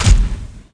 gunHit.mp3